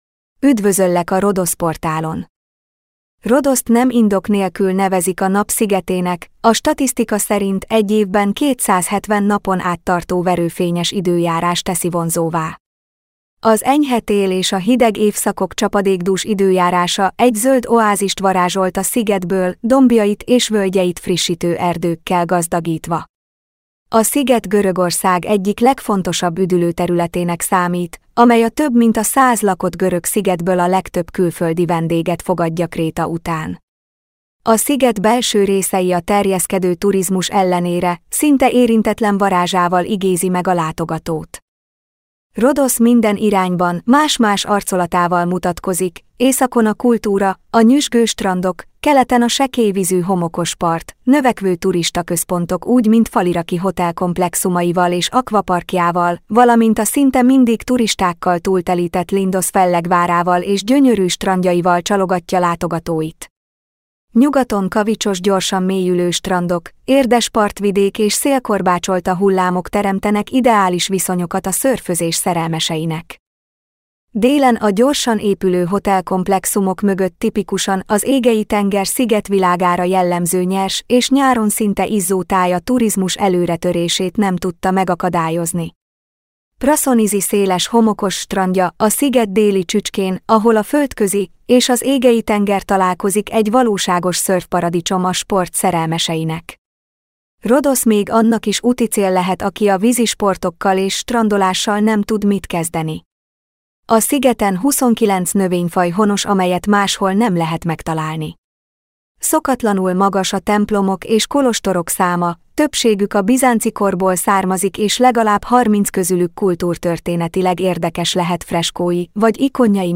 Felolvasom: